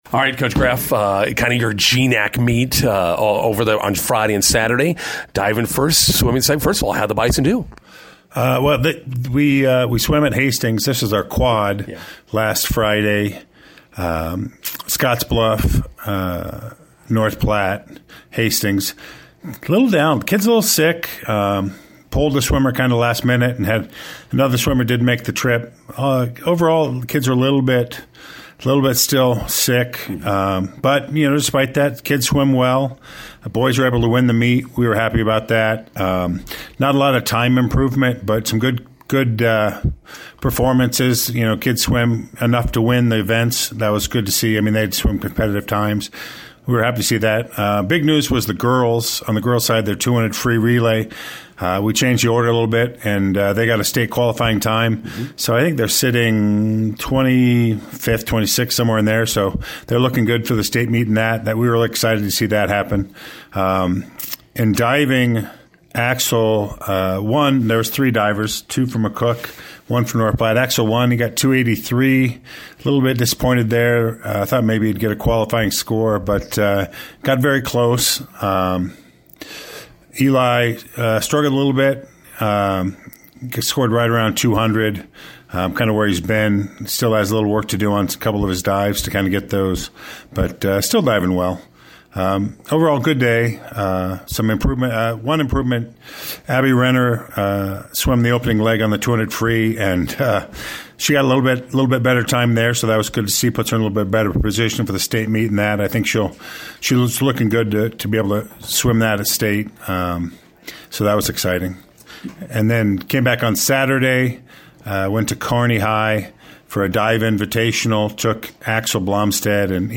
INTERVIEW: Bison swimmers/divers qualify a few more for the state meet.